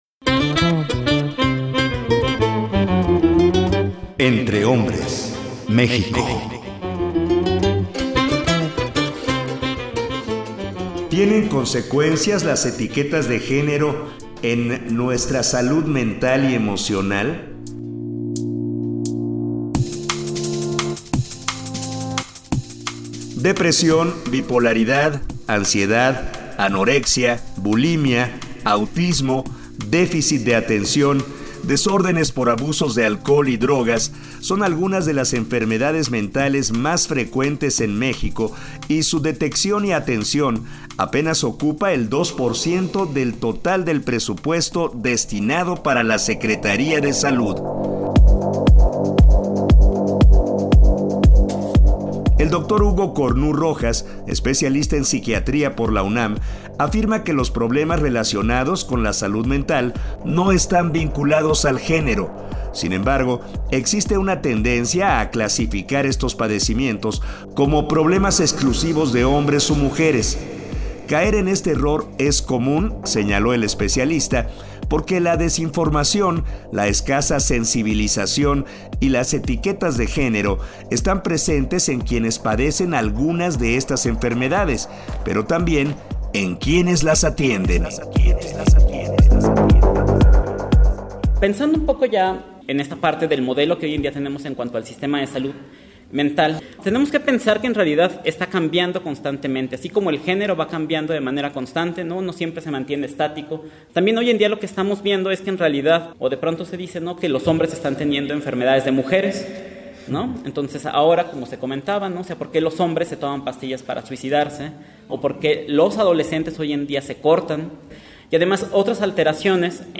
Conversatorio